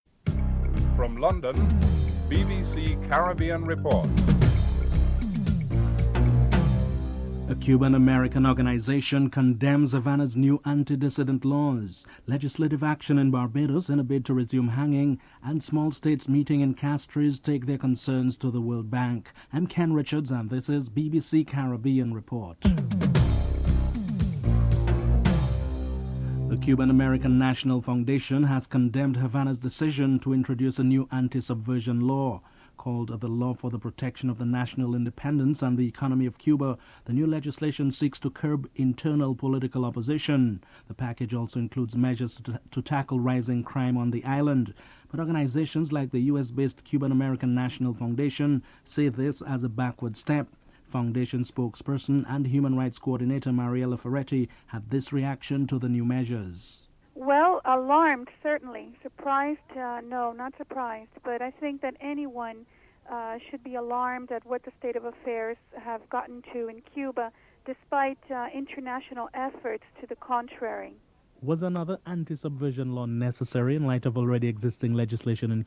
Republican and Democratic leaders comment on the benefits and on the passage of the bill.